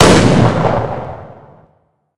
bigshotgun01.ogg